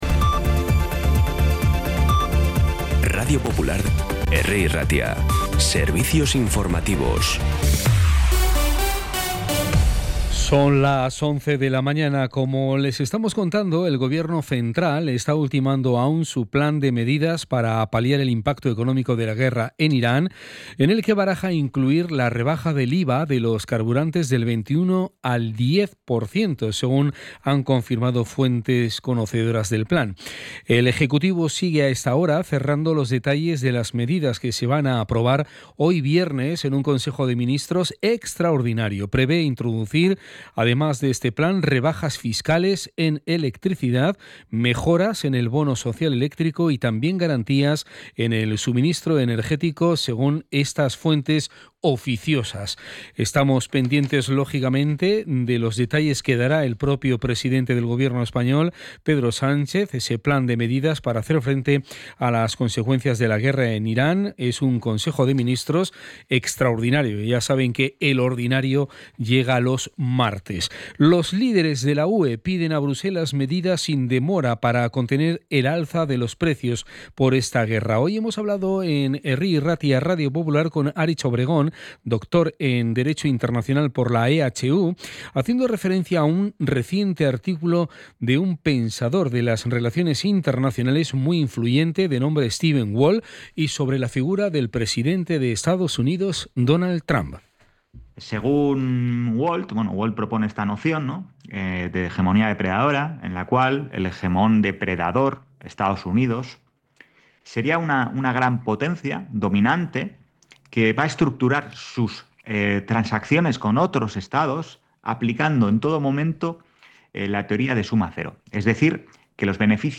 Las noticias de Bilbao y Bizkaia del 20 de marzo a las 11
Podcast Informativos
Los titulares actualizados con las voces del día. Bilbao, Bizkaia, comarcas, política, sociedad, cultura, sucesos, información de servicio público.